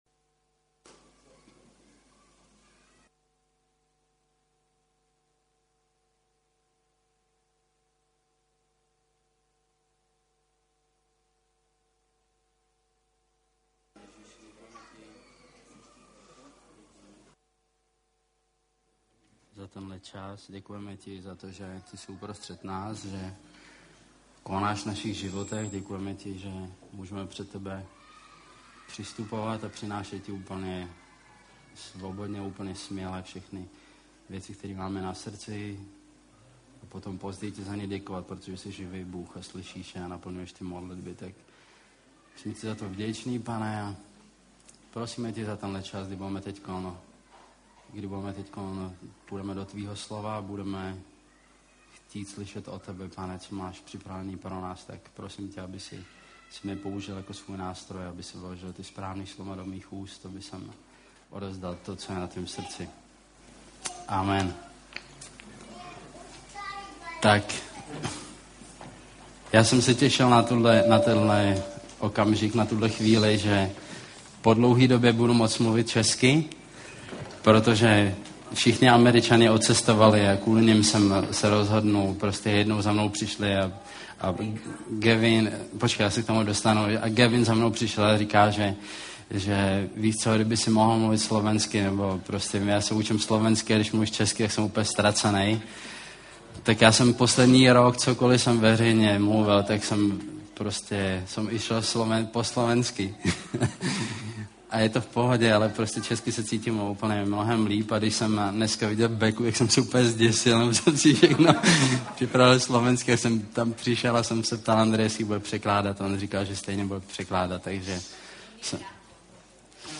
Nahrávka kázne Kresťanského centra Nový začiatok z 17. decembra 2006